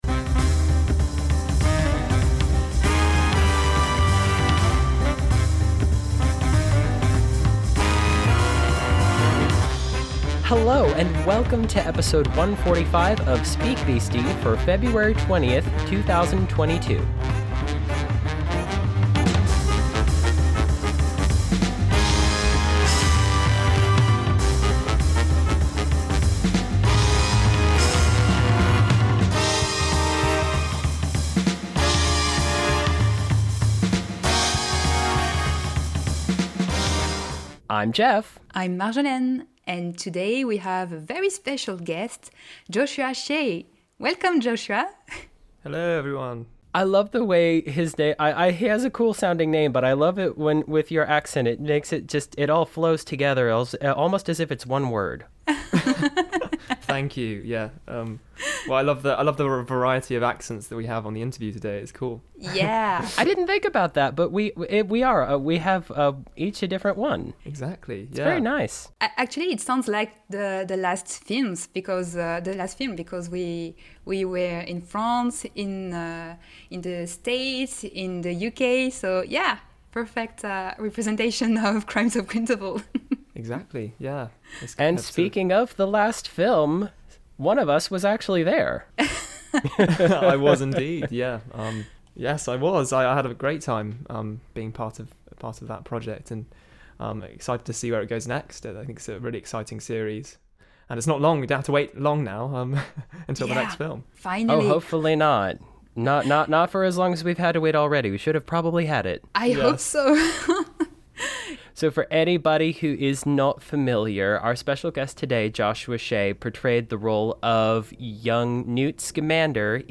Main Discussion: An interview